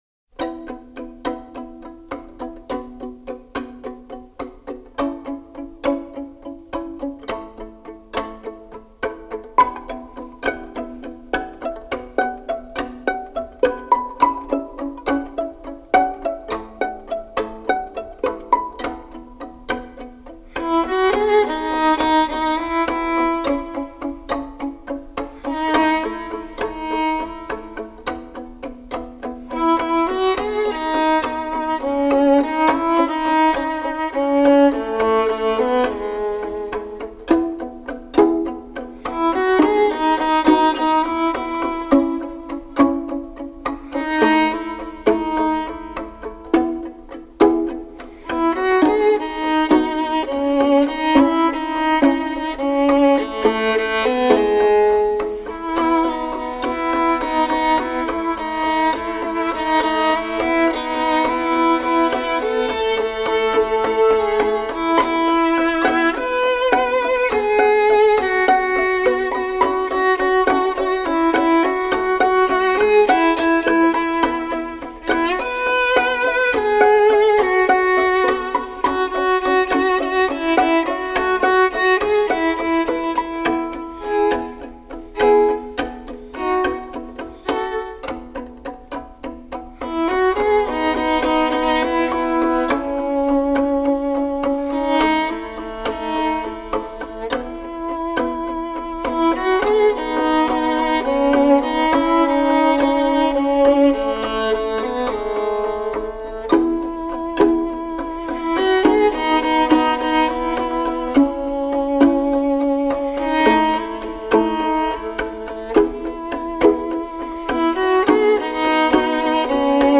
Cover Song